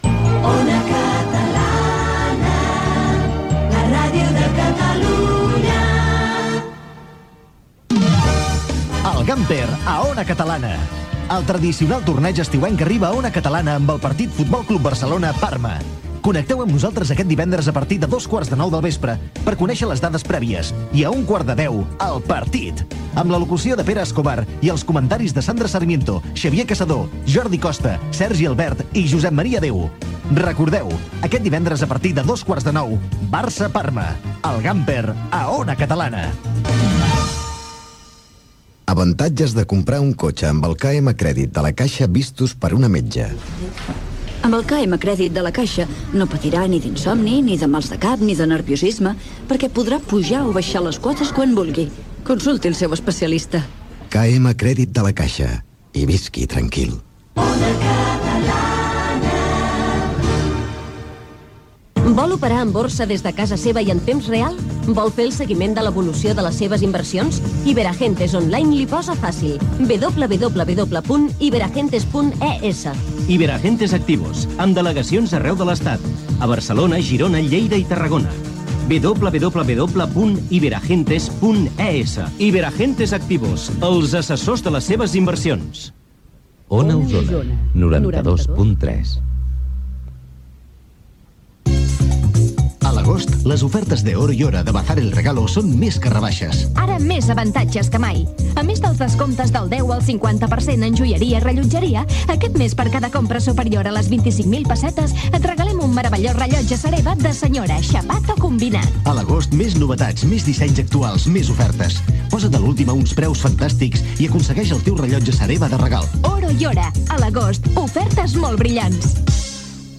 Indicatiu de la cadena, "El Gamper a Ona Catalana", publicitat, indicatiu de la cadena, publicitat, indicatiu de l'emissora, publicitat, resultat de la Loto 6 49, publicitat, indicatiu de l'emissora.